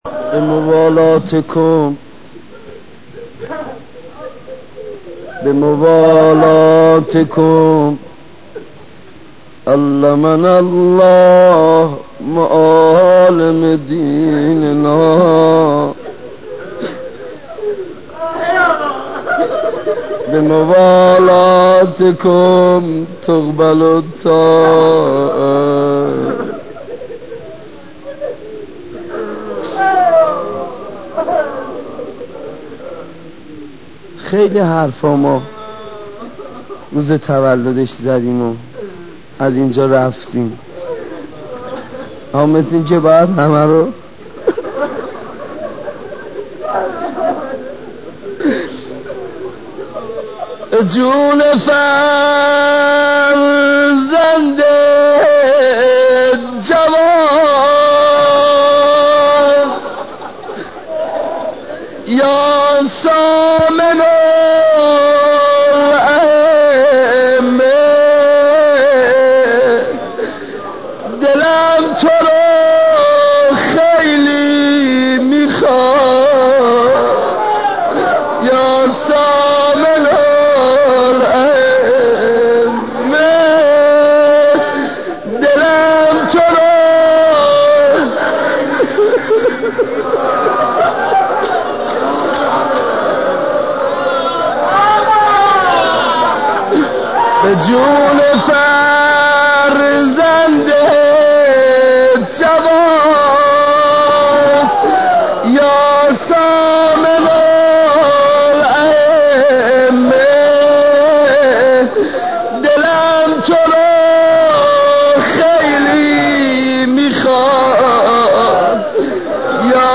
مداحی‌حاج‌ منصورارضی‌برای‌امام‌رضا(ع) در جمع رزمندگان دفاع مقدس صوت - تسنیم
خبرگزاری تسنیم: صوتی که می شنوید مربوط به یکی از مراسم های مداح اهل بیت(ع) حاج منصورارضی در مشهد مقدس می باشد که در حضور برخی از رزمندگان دوران جنگ در سال 1366 برگزار شده است.
این مراسم مربوط به روضه خوانی و سینه زنی برای حضرت رضا(ع) می باشد که تهران پرس بخشی از این مراسم را منتشر کرده است.